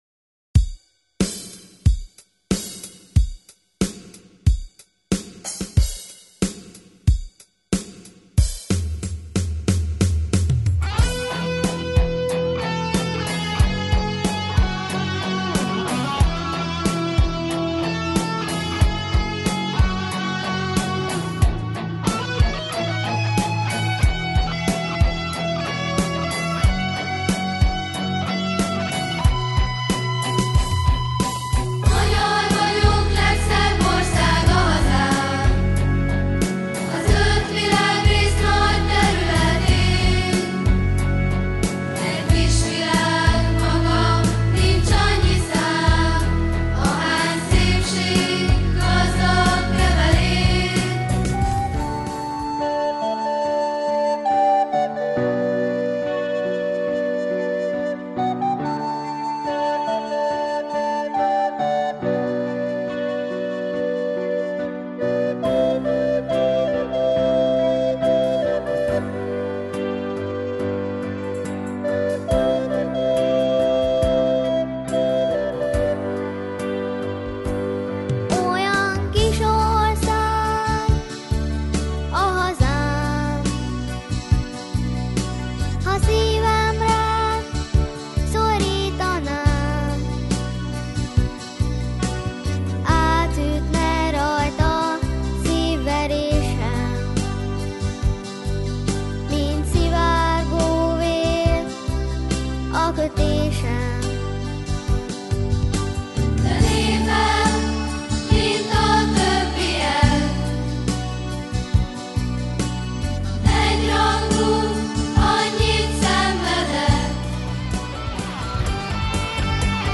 Gitáron közreműködik